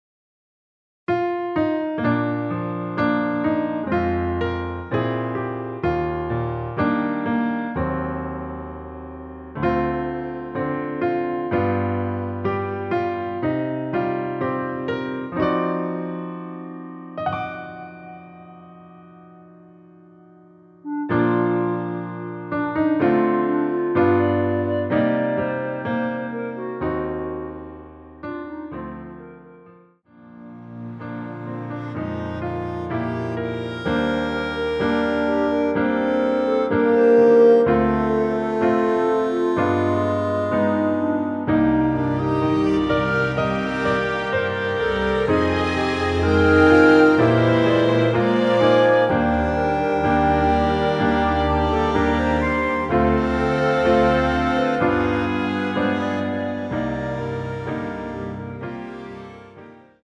원키에서(-2)내린 멜로디 포함된 MR입니다.
Bb
앞부분30초, 뒷부분30초씩 편집해서 올려 드리고 있습니다.
(멜로디 MR)은 가이드 멜로디가 포함된 MR 입니다.